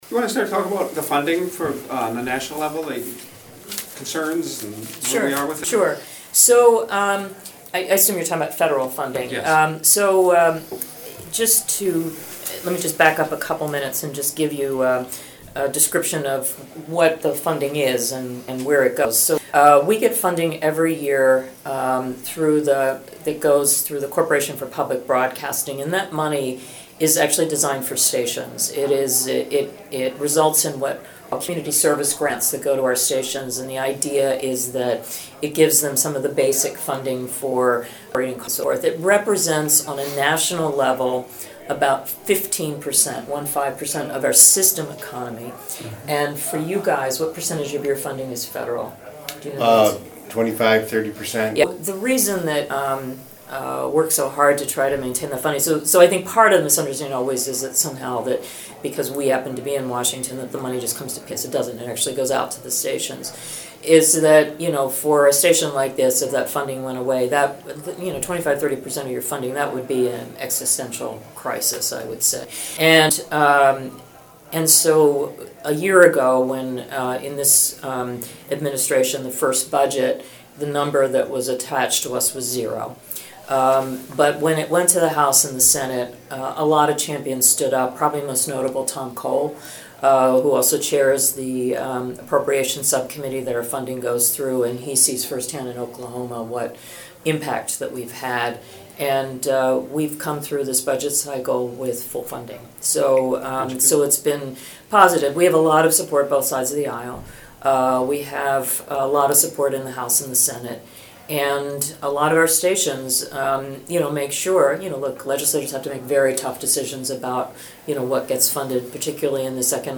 Paula Kerger President/CEO of Public Broadcasting PBS was in Granite Falls this past weekend to tour the new Pioneer Public Broadcasting studios and kickoff the Coming Into View Campaign. Paula held a press conference with local news media.